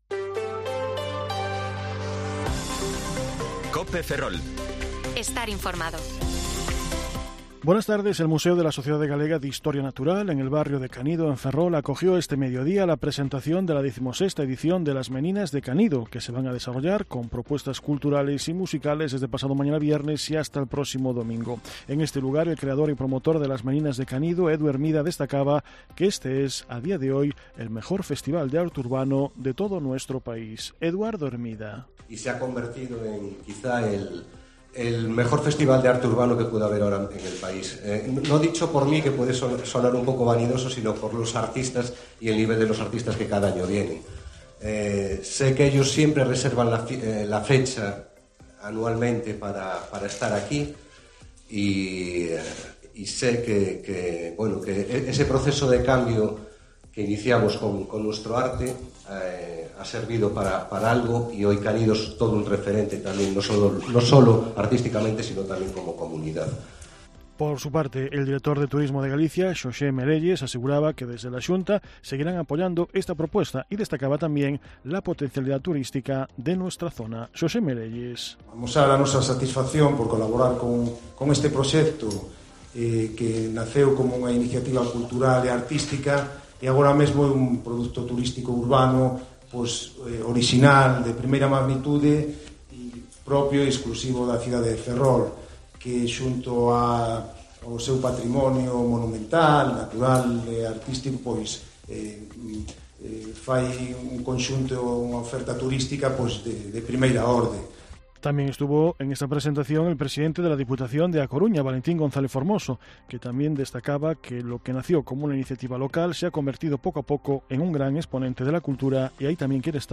Informativo Mediodía COPE Ferrol 30/8/2023 (De 14,20 a 14,30 horas)